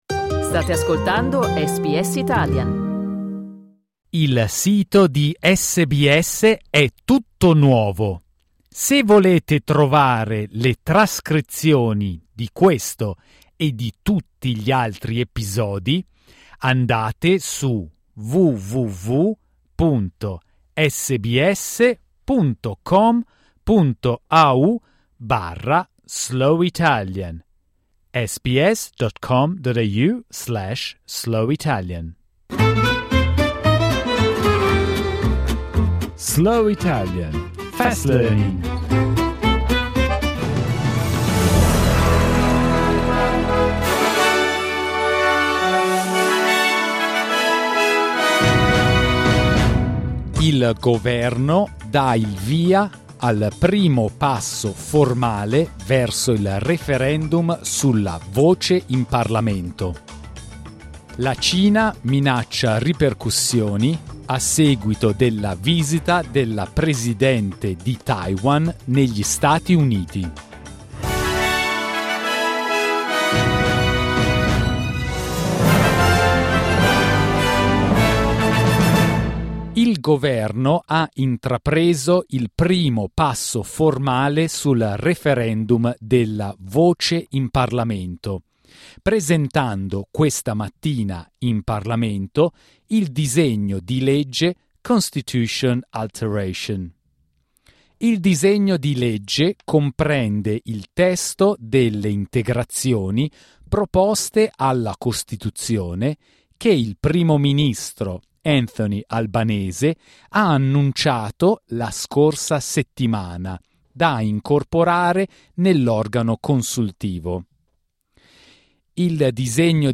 SBS Italian's News bulletin, read slowly.